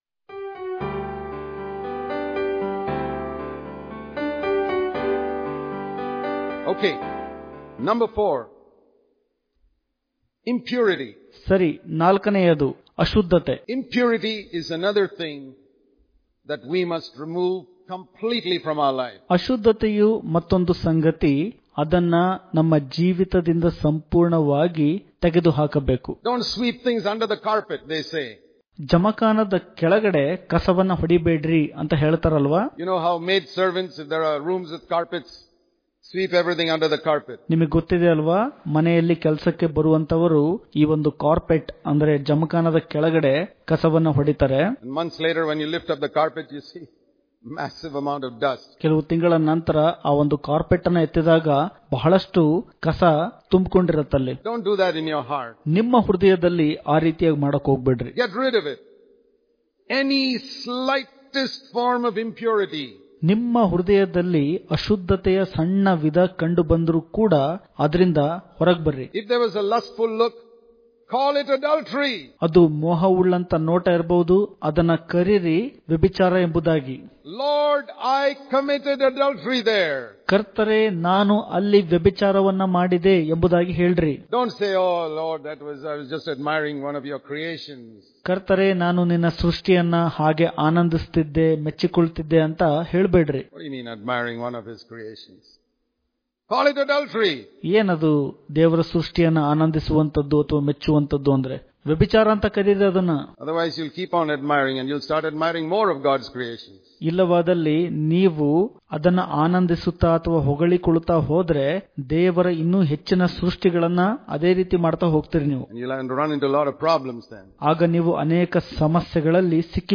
September 29 | Kannada Daily Devotion | Freedom From Impurity Daily Devotions